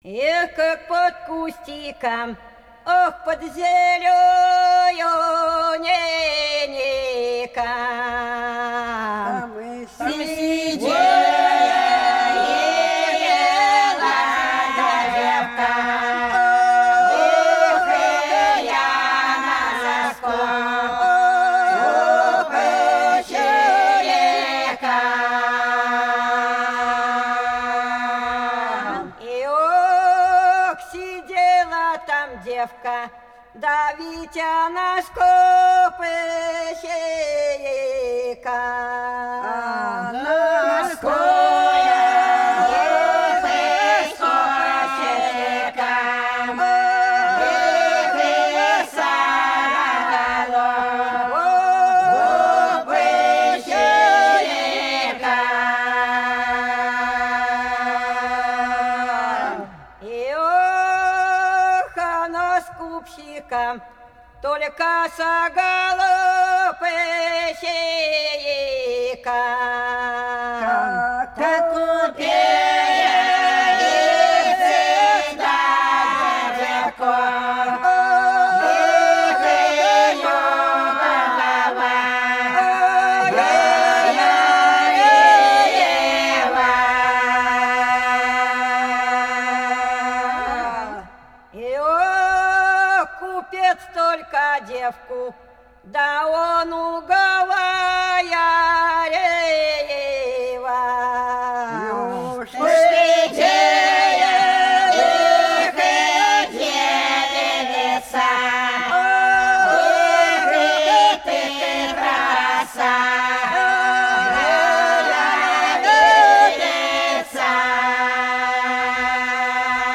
Пролетели все наши года Как под кустиком – протяжная (Фольклорный ансамбль села Пчелиновка Воронежской области)
21_Как_под_кустиком_–_протяжная.mp3